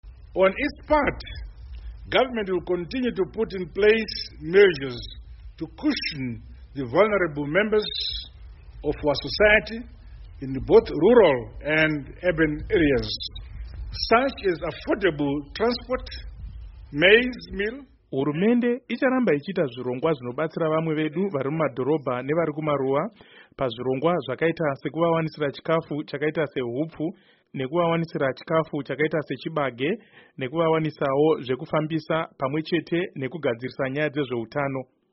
Mashoko a VaMnangagwa